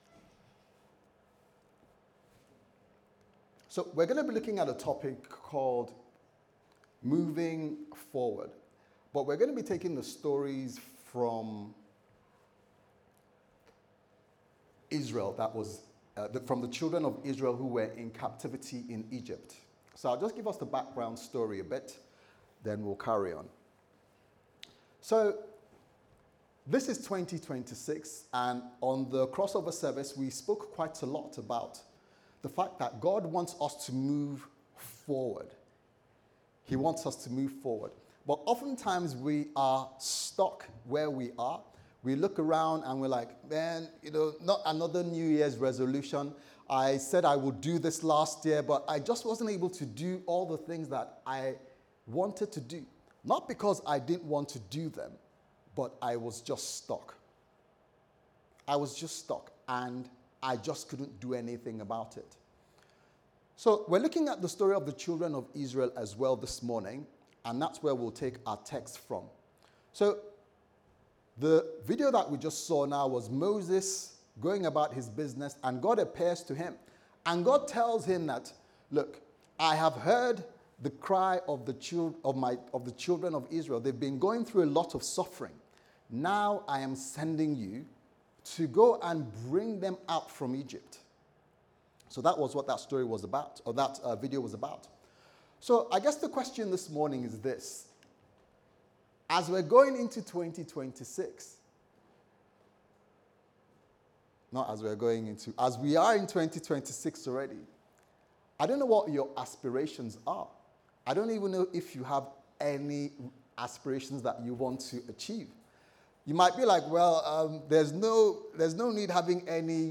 Priorities Service Type: Sunday Service Sermon « Moving Forward